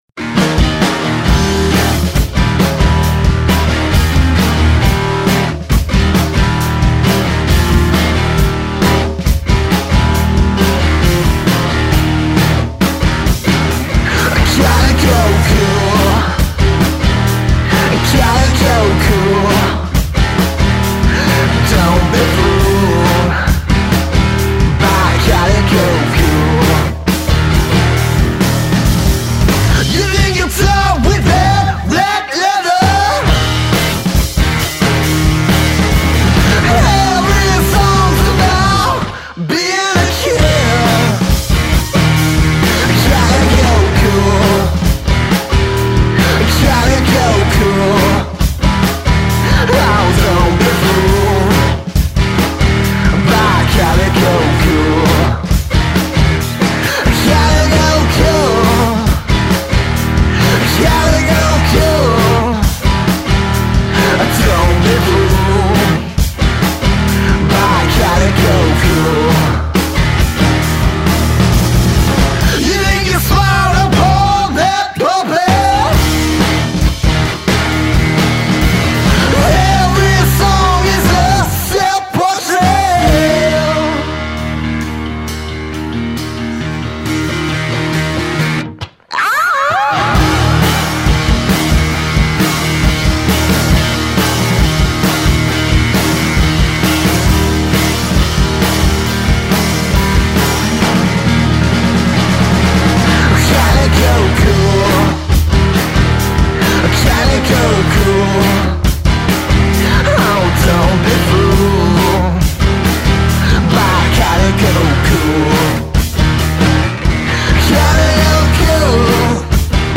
garage rock two-piece